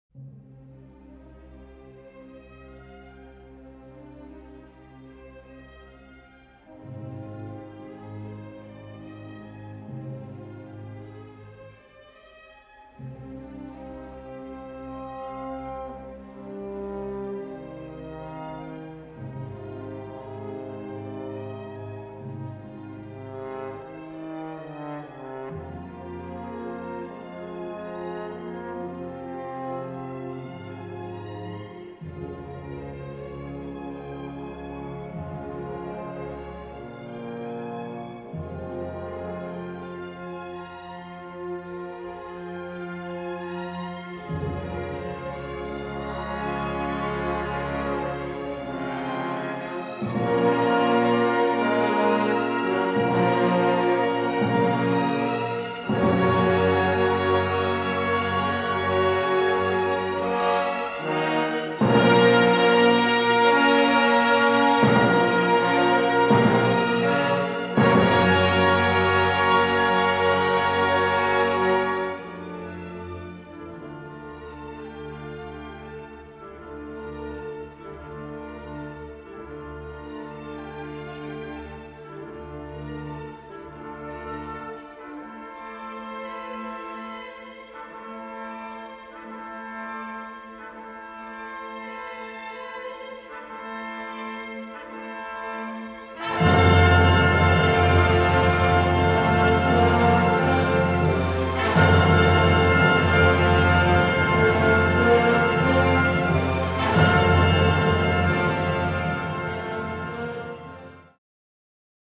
Gattung: Choral
Besetzung: Blasorchester
in c-Moll